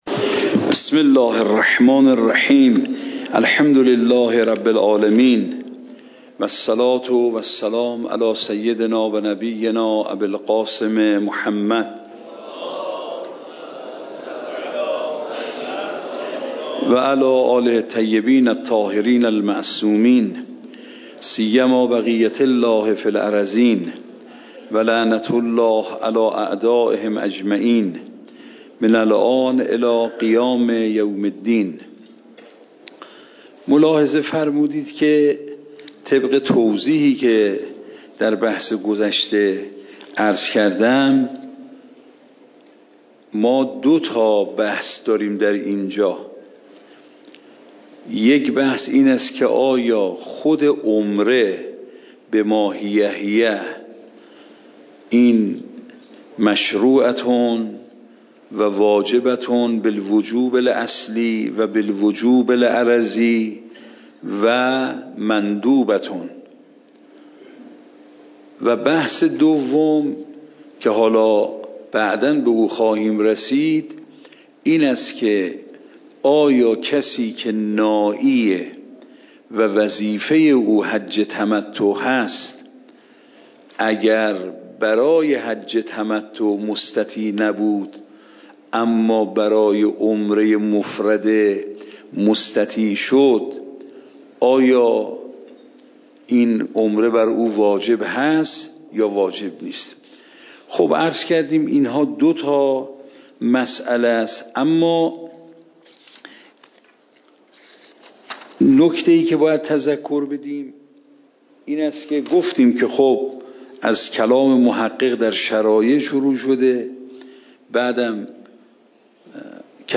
فقه خارج
صوت درس